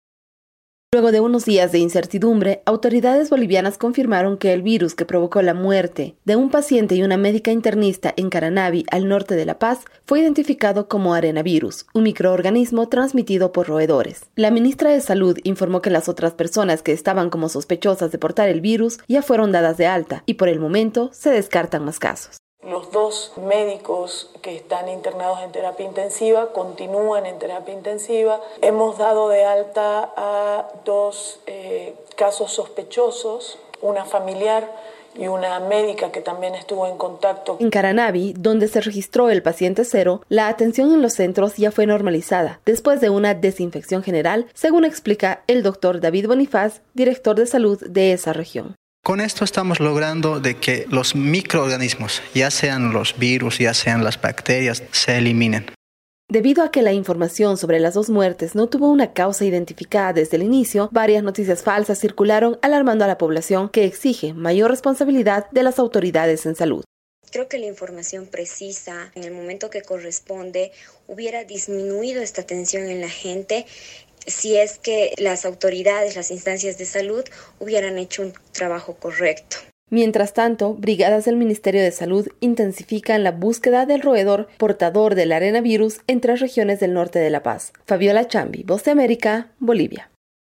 VOA: Informe desde Bolivia